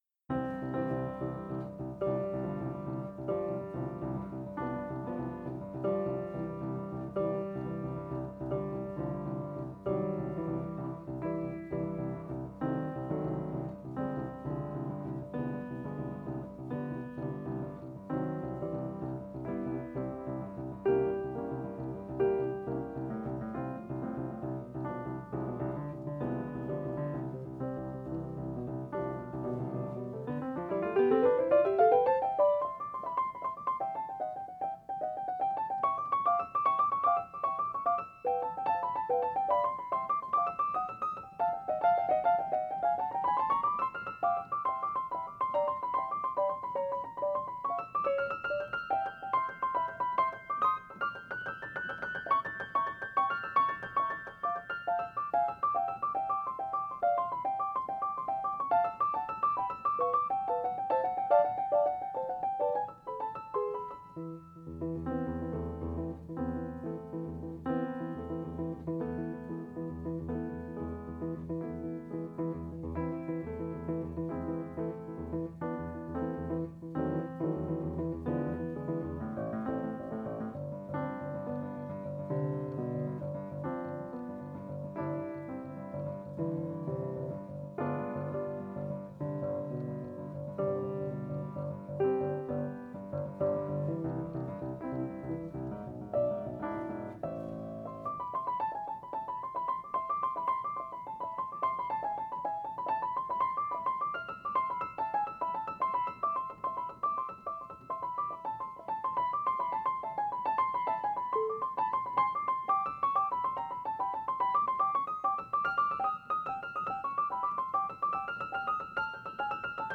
Also included is a recently restored professional recording
the open-reel tape was restored and digitized by George Blood Audio